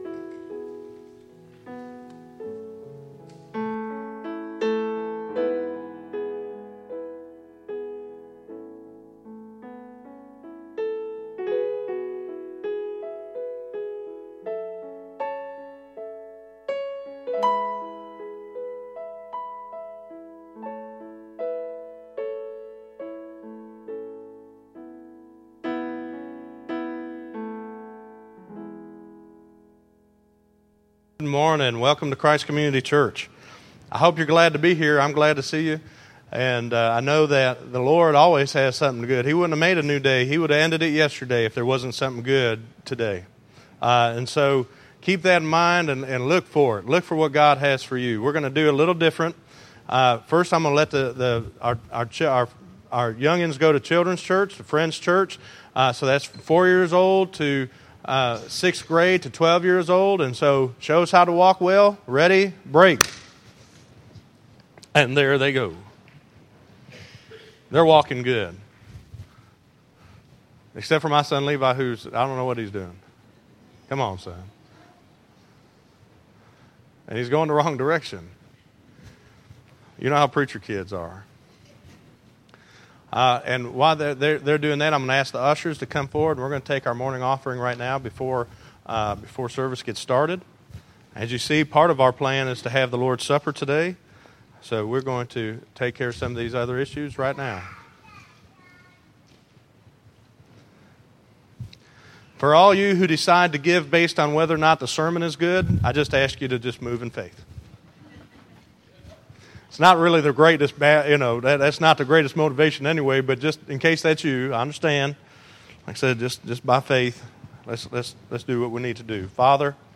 Listen to Prayer Life = Life Prayer 6 - 06_29_2014_Sermon.mp3